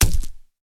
BodyHit01.wav